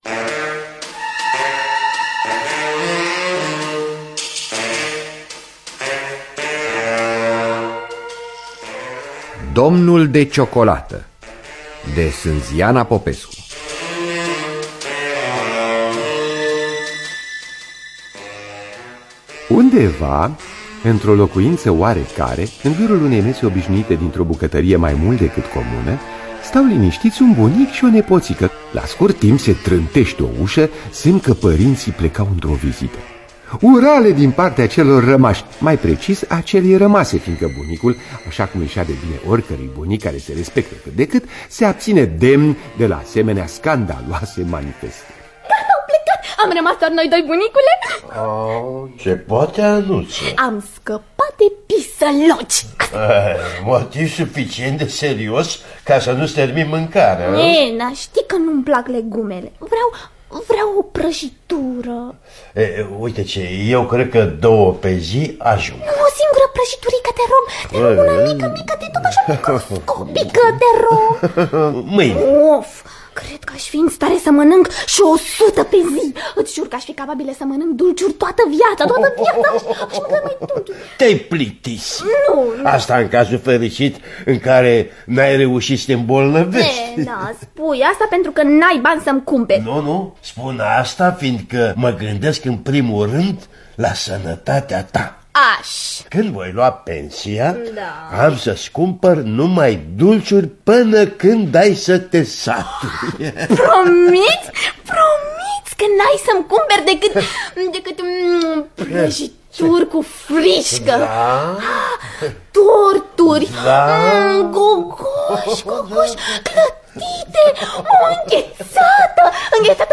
Sinziana Popescu – Domnul De Ciocolata (2002) – Teatru Radiofonic Online